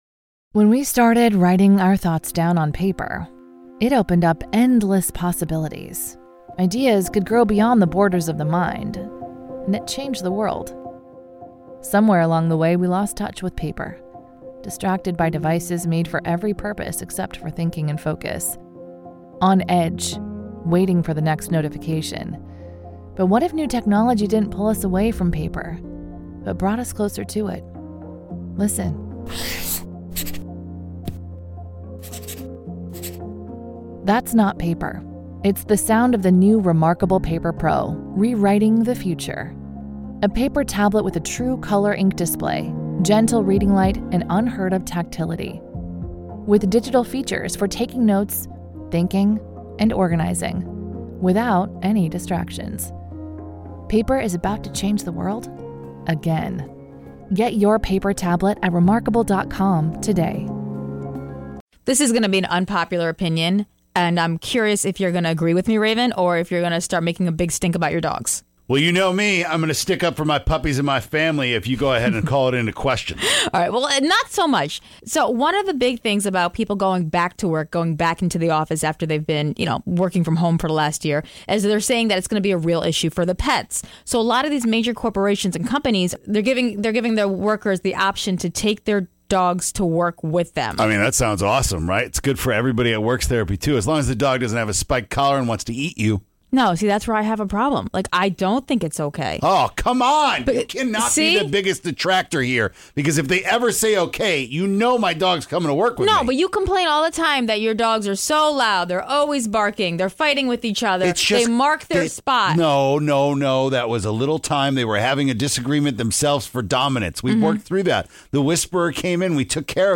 But don’t judge a book by it’s cover, one person who called in is an IT expert and you definitely wouldn’t know it to look at him!